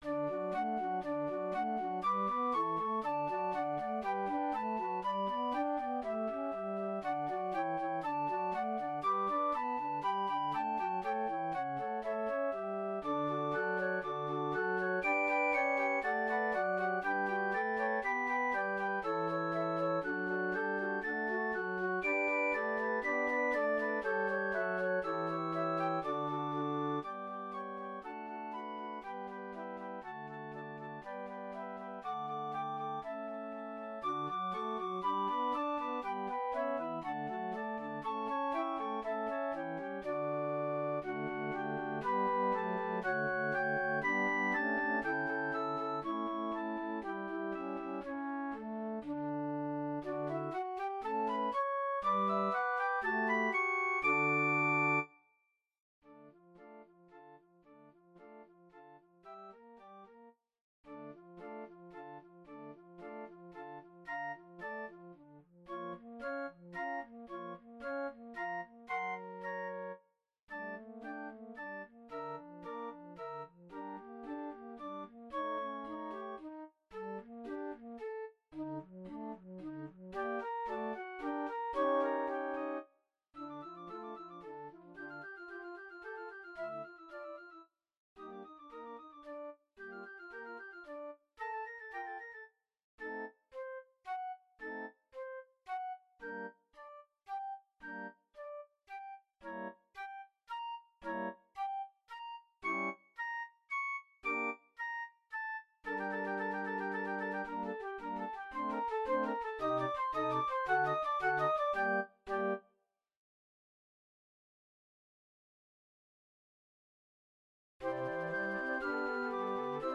Allegretto grazioso
1 Piccolo
3 C flutes
1 Alto flute
1 Bass flute